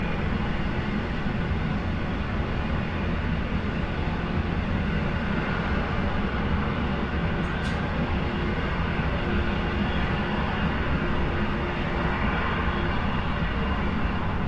描述：我使用了我自己在城市中的几段现场录音来创造这个城市景观。微妙的效果（混响、平移、EQ）增加了构图。
Tag: 城市 城市景观 大都市 街道 城市